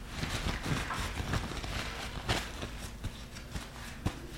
浴室 " 卫生间纸巾
描述：手纸巾
Tag: 浴室 齐平 马桶